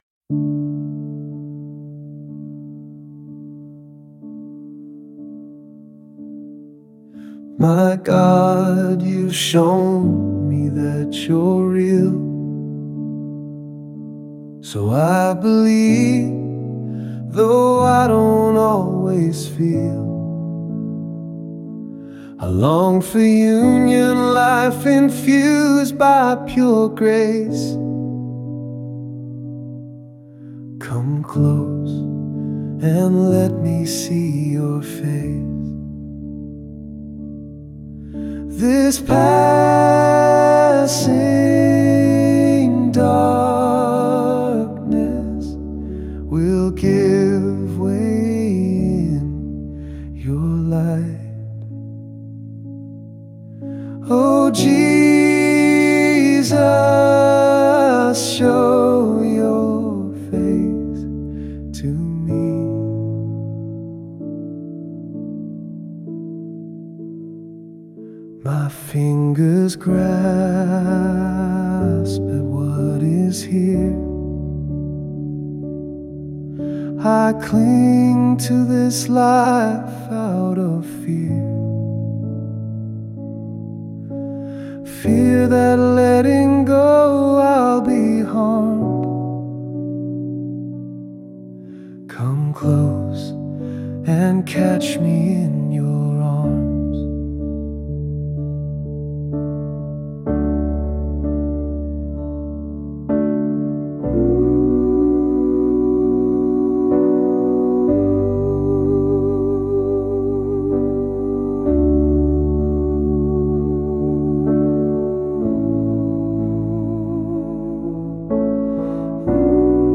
Mainstream